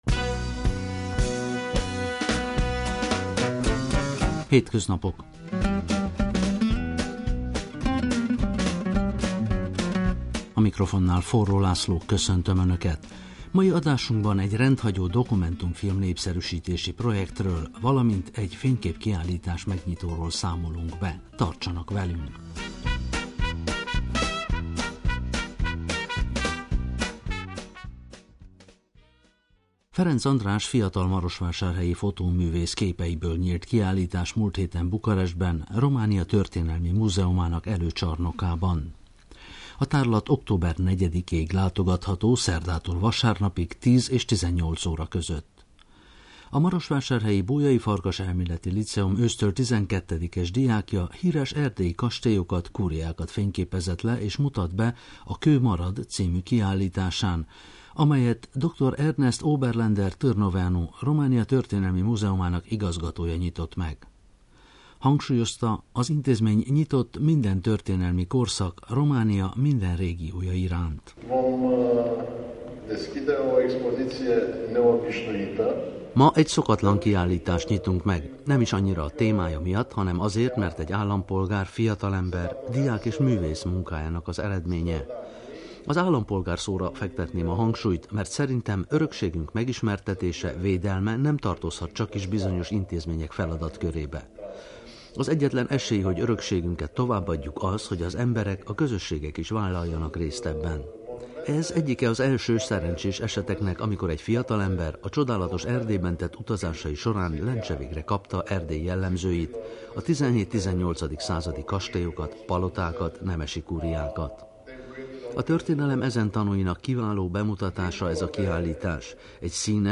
Ott készült első összeállításunk.